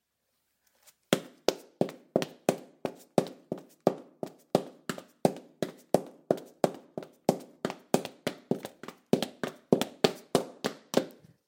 女人穿高跟鞋在停车场上跑步
描述：女人穿高跟鞋在停车场上跑步
标签： 奔跑 脚跟 车库 脚步 停车场 女人
声道单声道